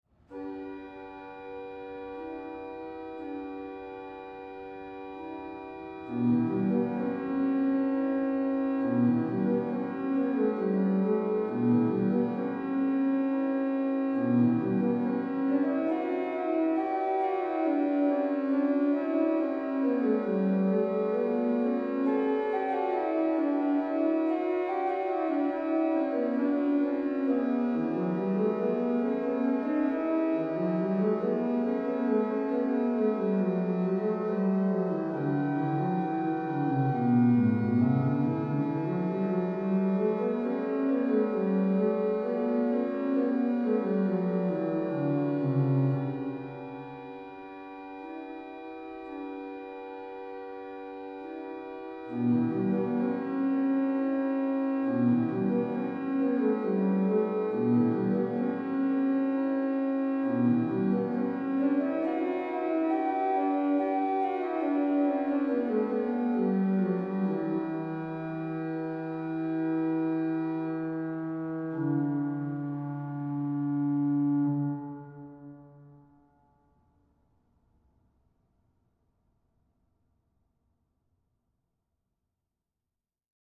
organ Click to listen.